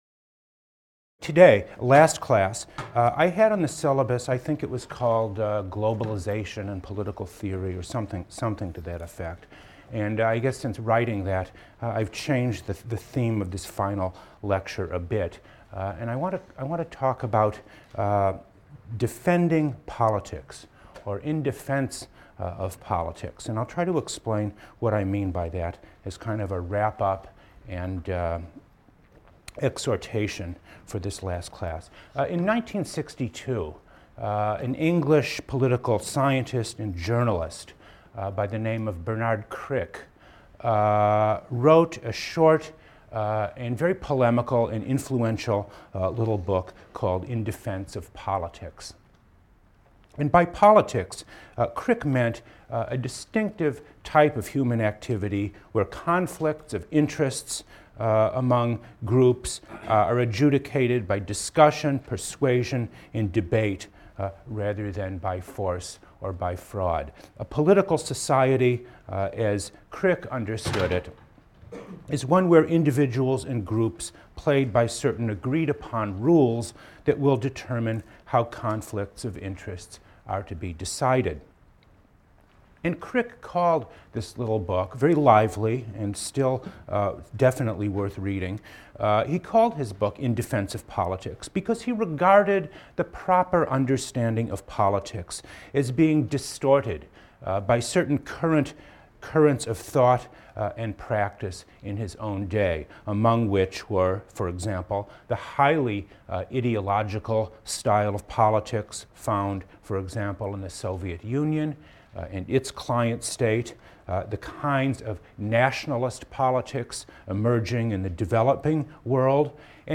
PLSC 114 - Lecture 24 - In Defense of Politics | Open Yale Courses